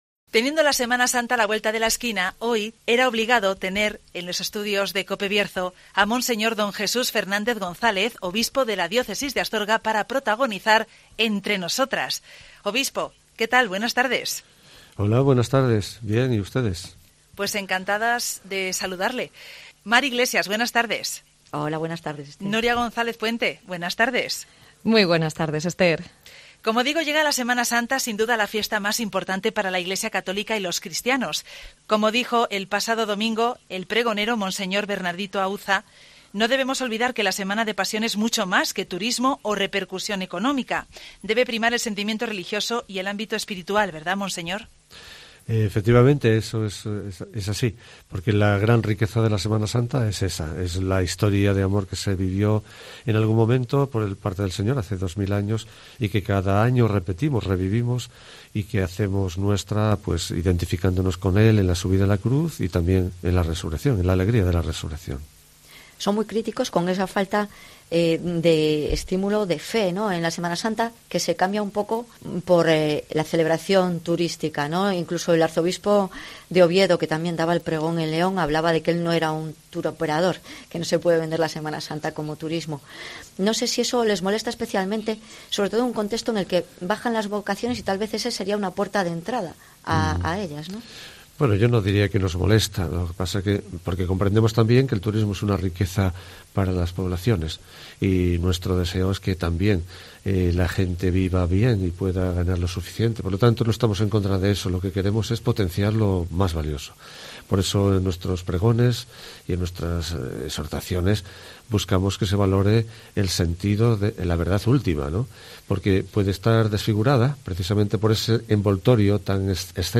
Monseñor Don Jesús Fernández González, Obispo de la Diócesis de Astorga, visita COPE Bierzo ante el inicio de la Semana de Pasión
Ante la próxima celebración de la Semana Santa hoy protagoniza el espacio "Entre Nosotras" de COPE León, Mons. Don Jesús Fernández González, Obispo de la Diócesis de Astorga.